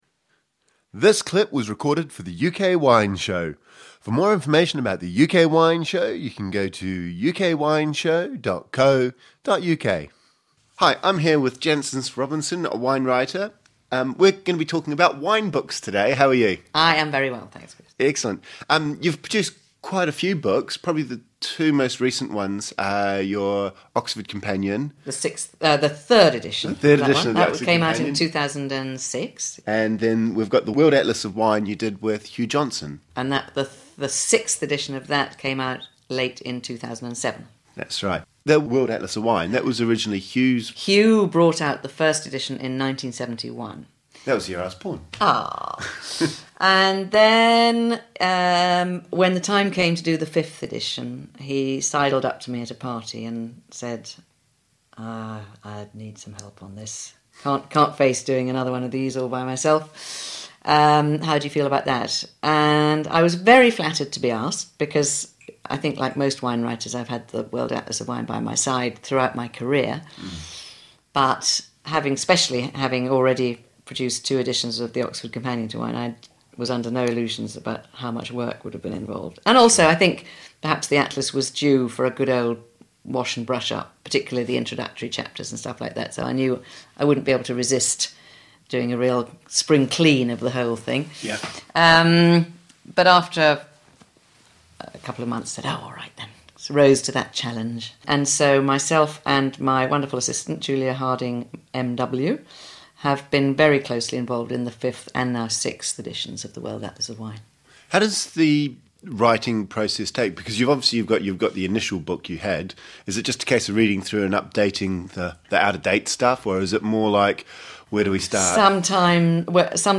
Jancis Robinson is a world-leading wine writer and author of books including The World Atlas of Wine and the Oxford Companion. In this second interview we focus on her writing and what it involves.